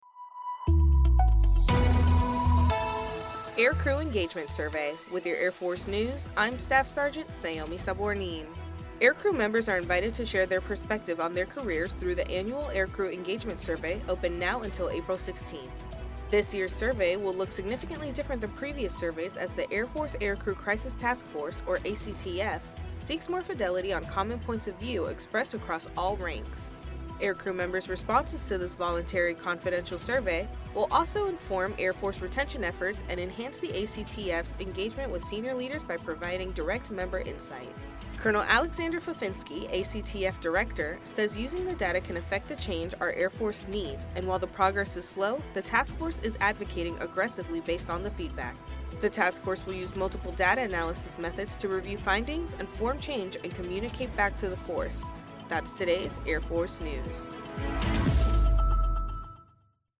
Features audio news stories involving U.S. Air Force technology, personnel, and operations around the globe.